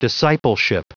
Prononciation du mot discipleship en anglais (fichier audio)
discipleship.wav